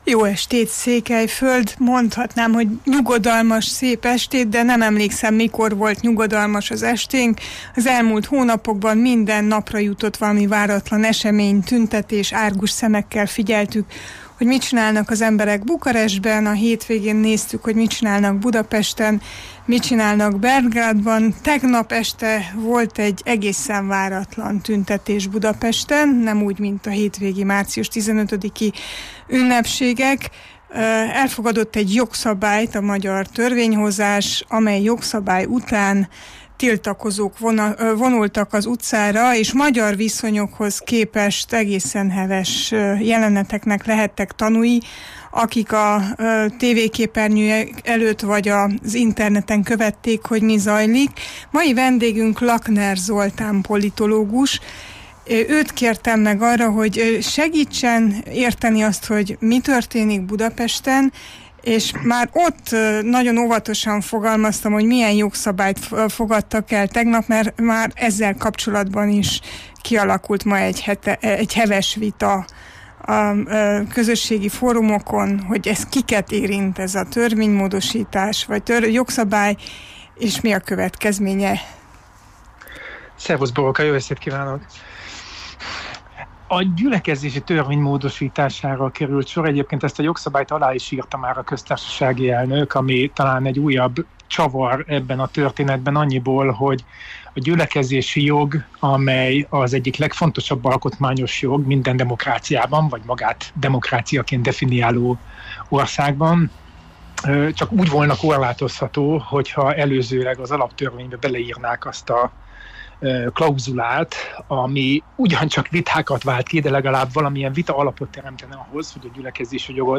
politikussal arról beszélgettünk